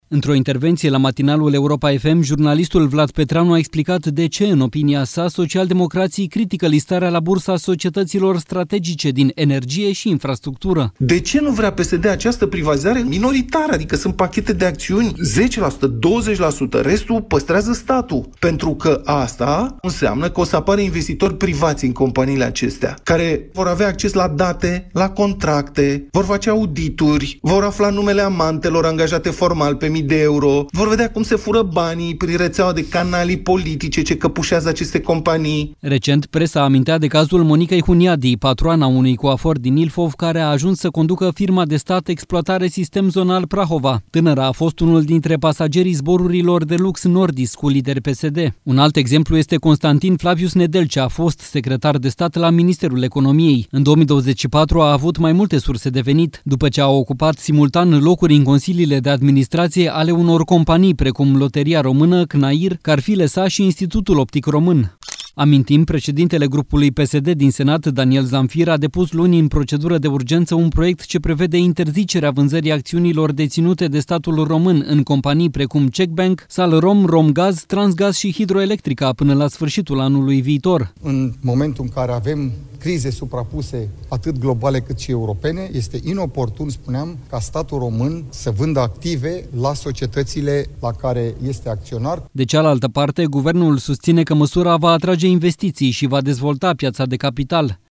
Într-o intervenție la matinalul Europa FM